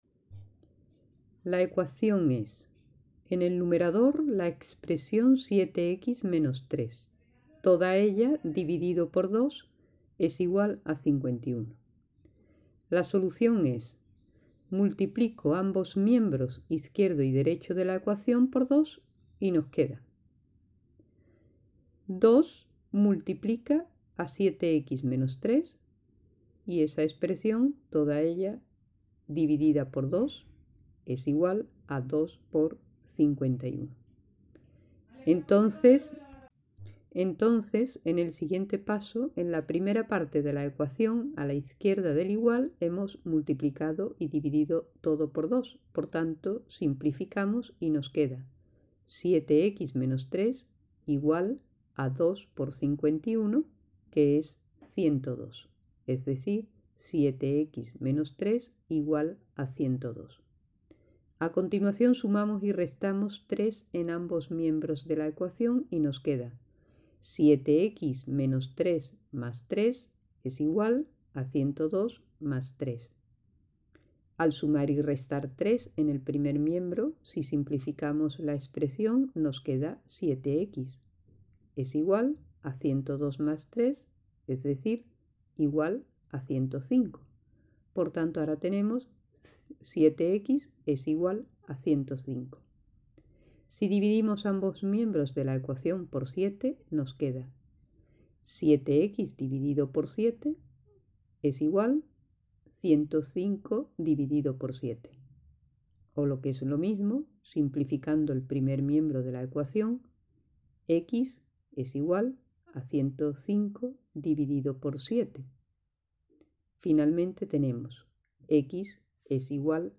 Y, a continuación, este ejercicio debe ser acompañado por la descripción grabada en audio.